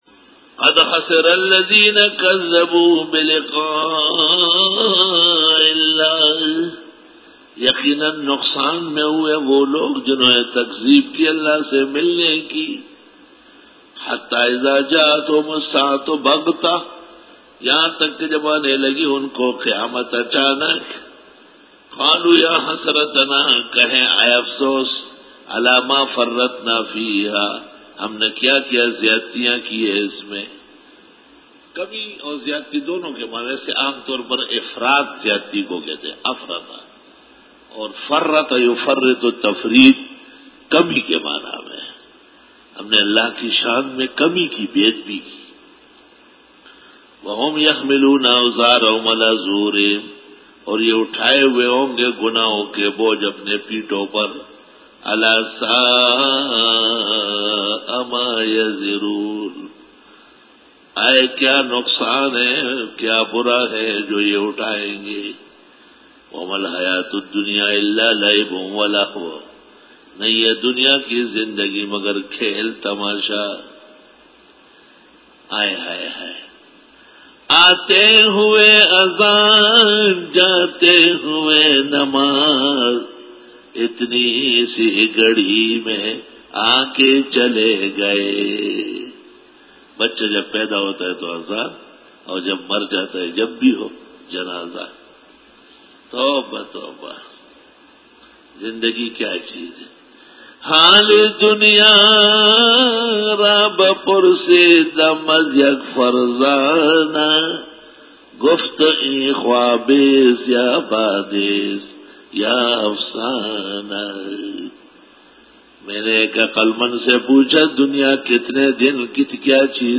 Dora-e-Tafseer 2011